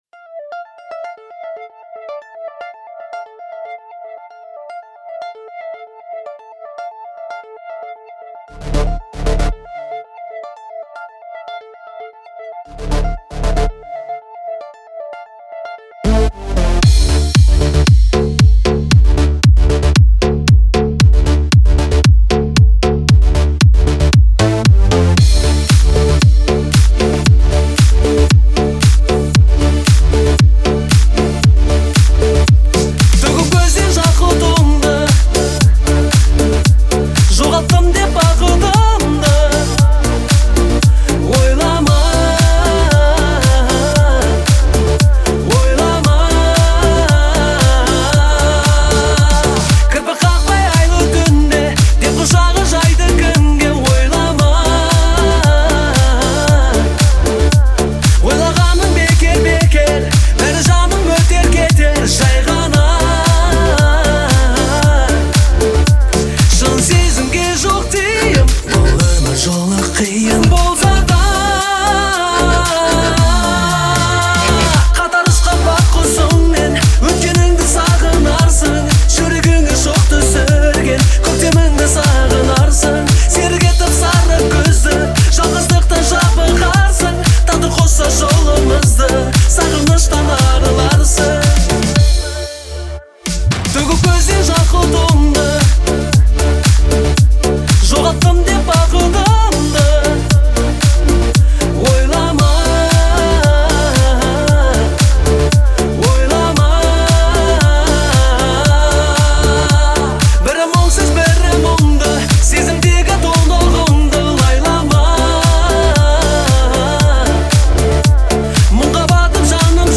это яркий пример казахского поп-фолка